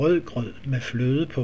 - Say after me 'red porridge with cream'1), and in Vanløsees